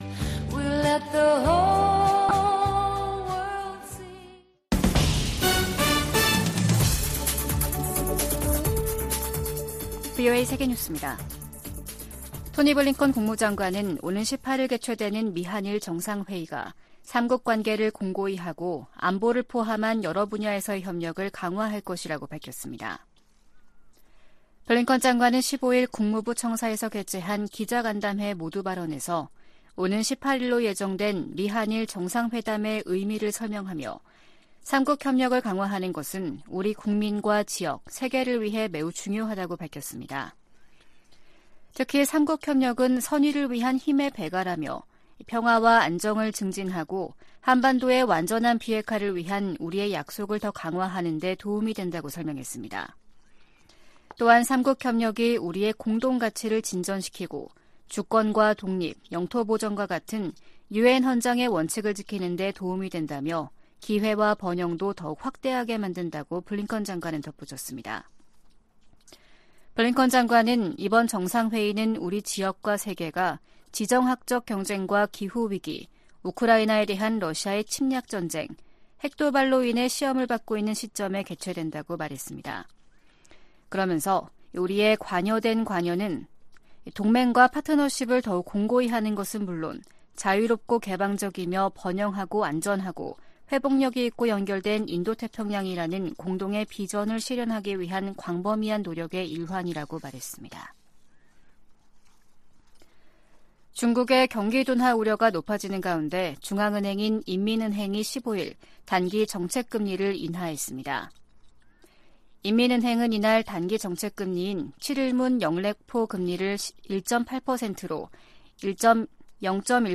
VOA 한국어 아침 뉴스 프로그램 '워싱턴 뉴스 광장' 2023년 8월16일 방송입니다. 오는 18일 열리는 캠프데이비드 미한일 정상회의에서 3국 협력을 제도화하는 방안이 나올 것으로 전망되고 있습니다. 미국 국방부는 미한일 군사훈련 정례화 문제와 관련해 이미 관련 논의가 있었음을 시사했습니다. 윤석열 한국 대통령은 광복절 경축사에서 북한에 대한 ‘담대한 구상’ 제안을 재확인하면서 압도적 힘에 의한 평화 구축을 강조했습니다.